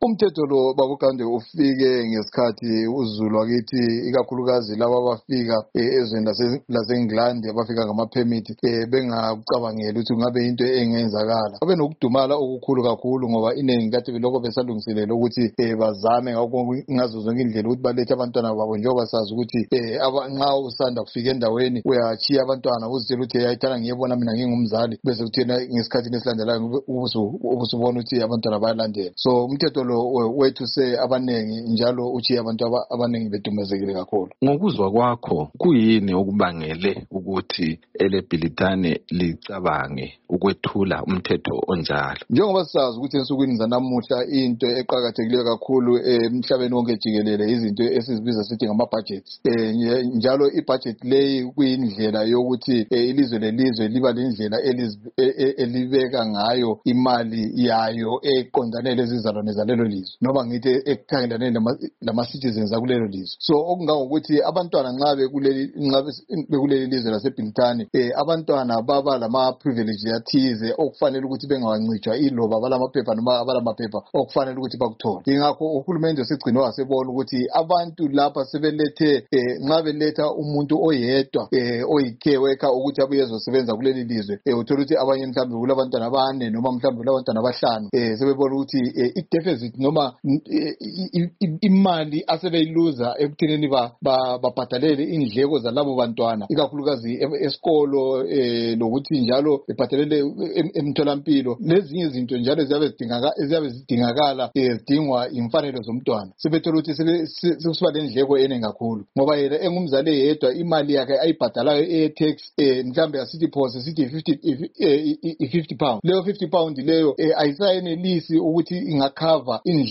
Ingxoxdo